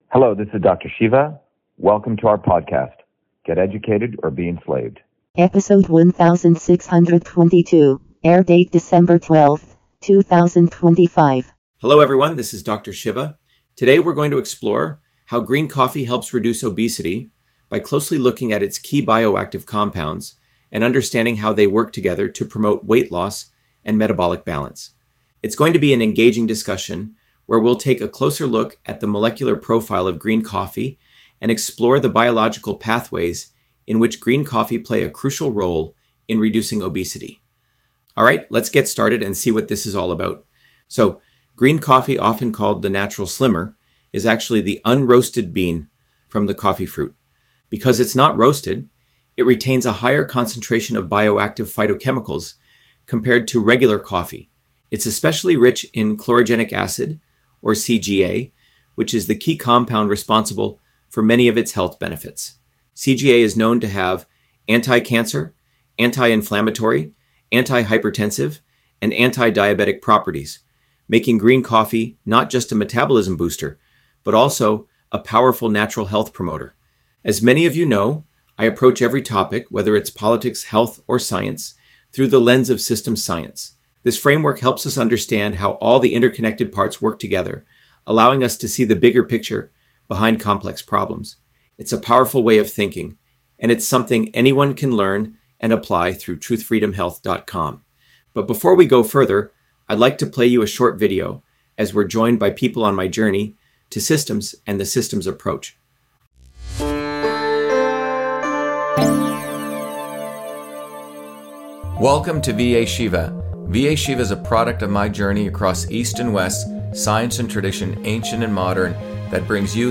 In this interview, Dr.SHIVA Ayyadurai, MIT PhD, Inventor of Email, Scientist, Engineer and Candidate for President, Talks about Green Coffee on Obesity: A Whole Systems Approach